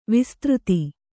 ♪ vistřti